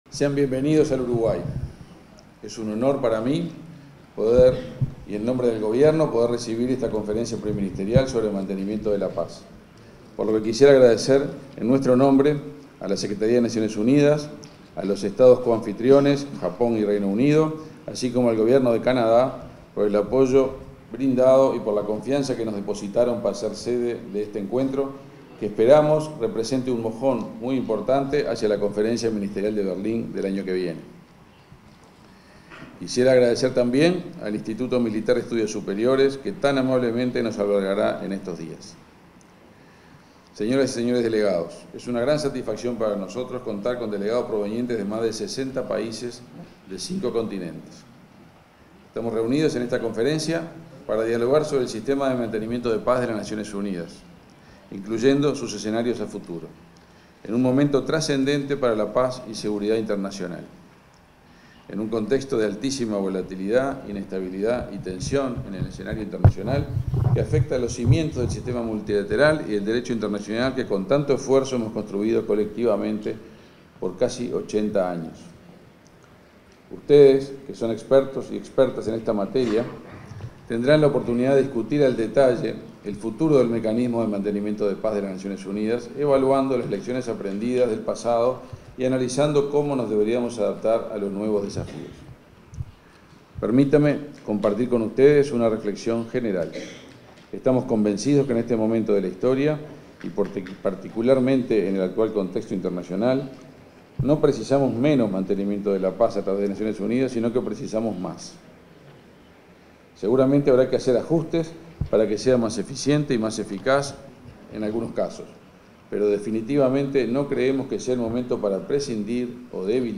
Palabras del los ministros de Relaciones exteriores y de Defensa Nacional
Palabras del los ministros de Relaciones exteriores y de Defensa Nacional 10/12/2024 Compartir Facebook X Copiar enlace WhatsApp LinkedIn En el marco de la Reunión Preparatoria Ministerial de las Naciones Unidas sobre Mantenimiento de la Paz, este 10 de diciembre, se expresaron los ministros de Defensa Nacional, Armando Castaingdebat y de Relaciones Exteriores, Omar Paganini.